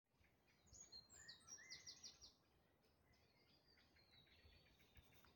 Der Vogel ließ sich blicken und sang vor mir weiter.
Es war ein Zaunkönig.
Zunächst sang er.
Zaunkoenig-Stimme-1.mp3